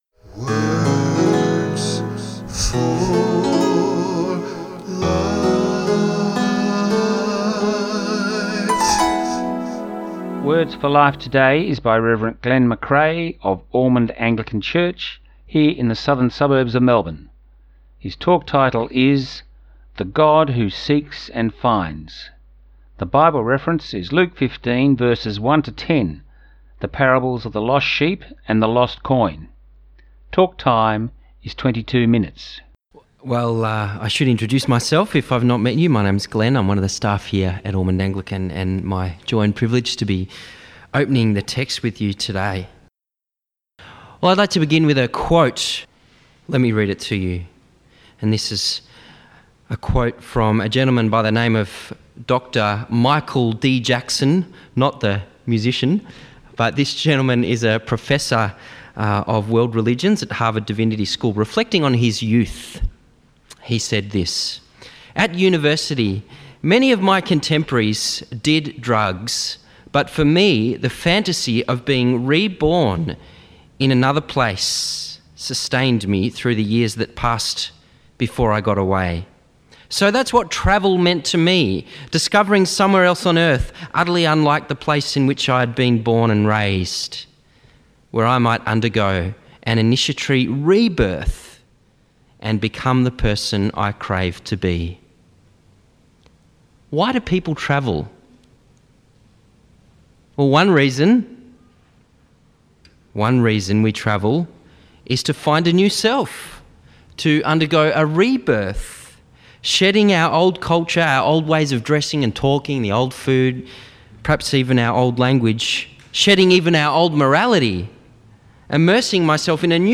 The Bible reference is Luke 15:1-10. Talk time is 22 minutes.